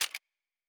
Weapon 07 Foley 2.wav